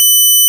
5-sin_fd192khz.wav